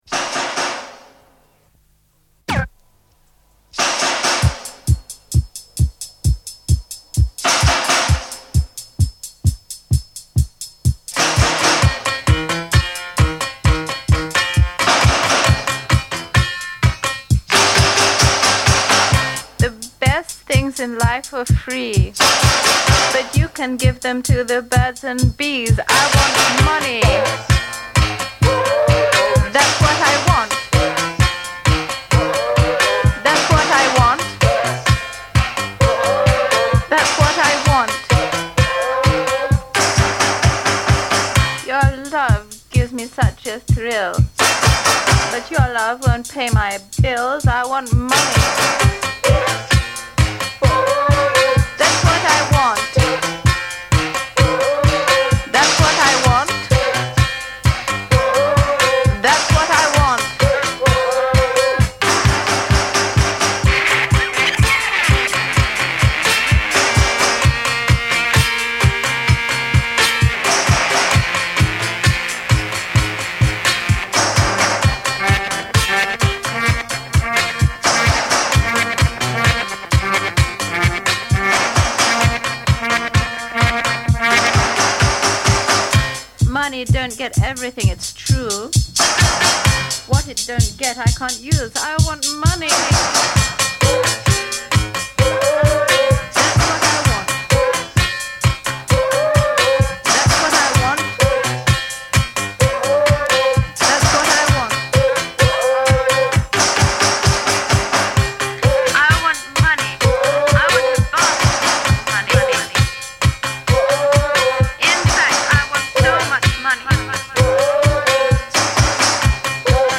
extended mix